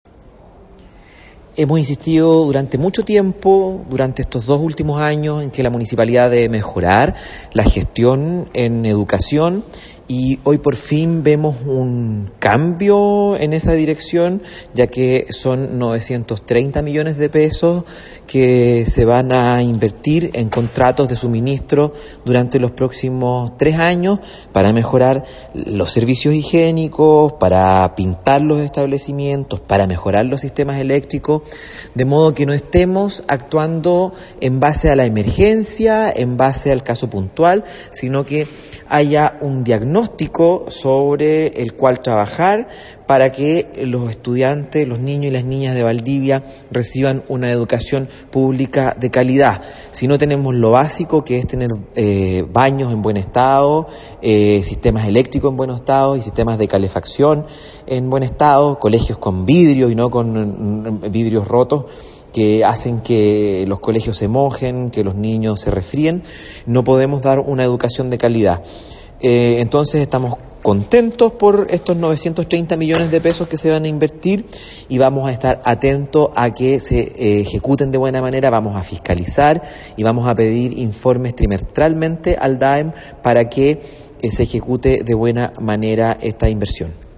Concejal de Valdivia, Pedro Muñoz Leiva y secretario de la Comisión de Educación